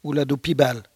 Localisation Saint-Hilaire-de-Riez
Langue Maraîchin
Catégorie Locution